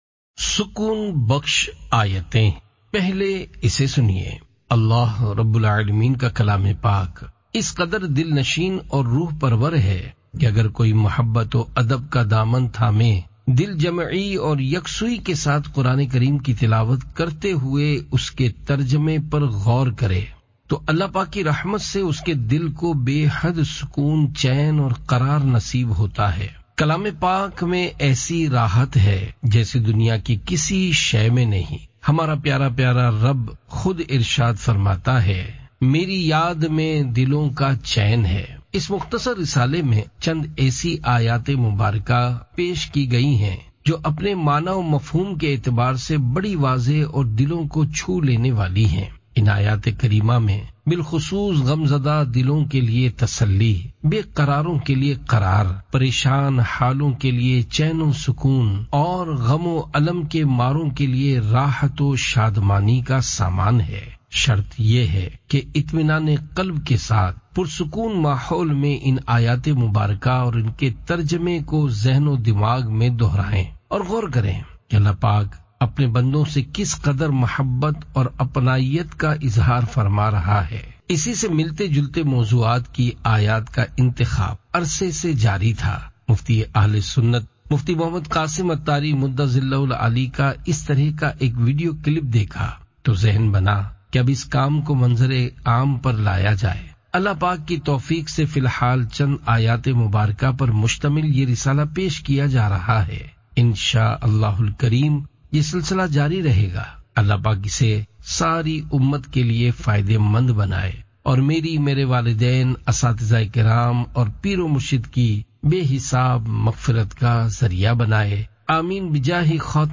Audiobook - Sukoon Bakhsh Aayaten (Urdu)